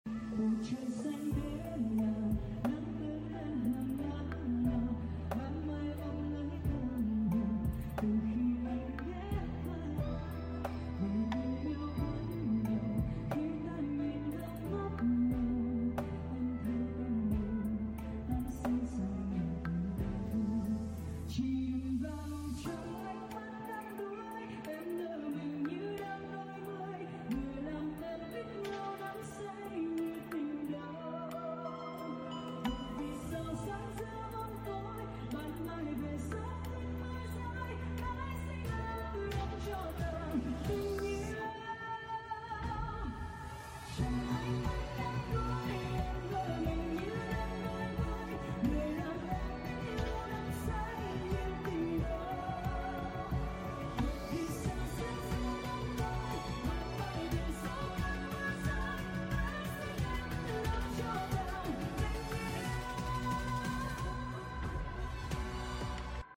Anh cho em xin phép hát lại 1 đoạn ca khúc này nha anh 🥰